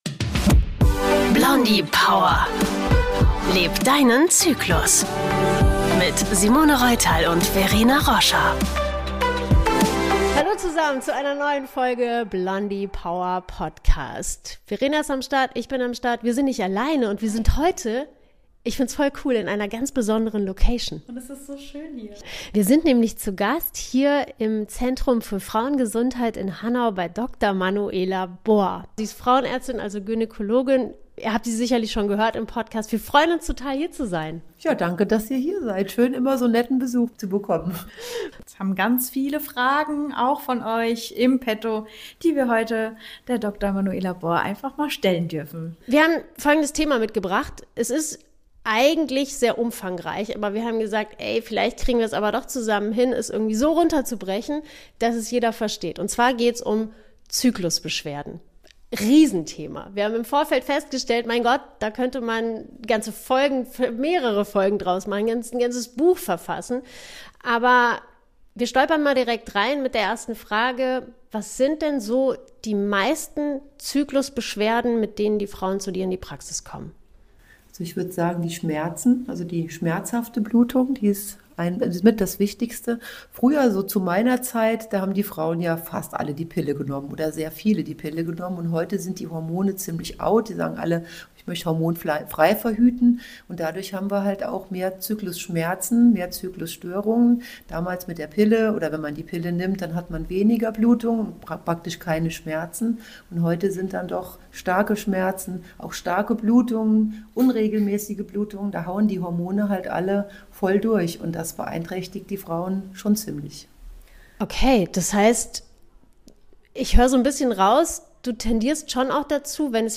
In dieser neuen Folge Blondie Power Podcast sind wir zu Gast im Zentrum für Frauengesundheit Hanau